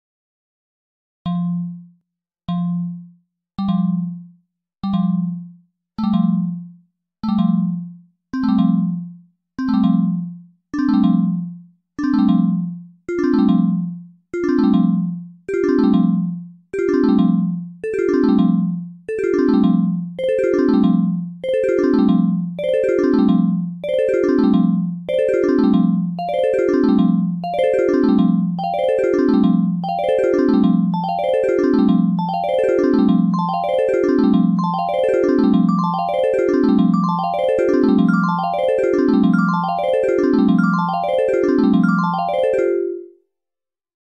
Optical Illusion: The Circle Metronomes sound effects free download